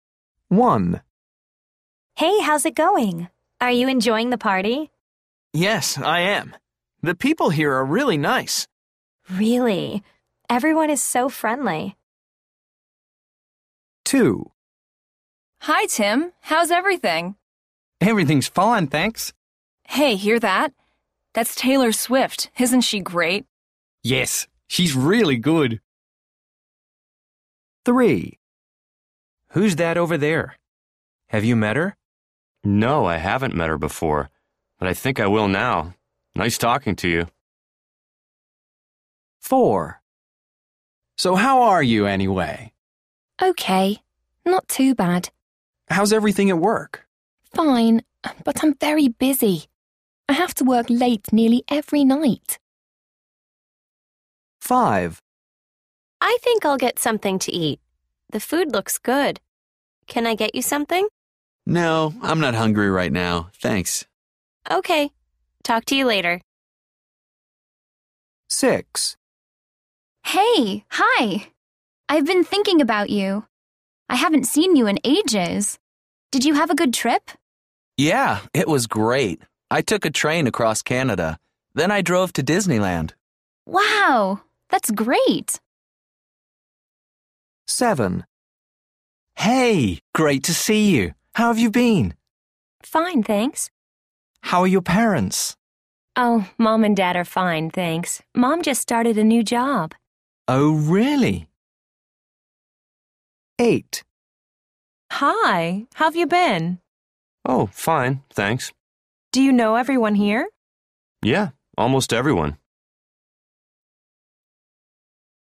A. What are the party guests talking about?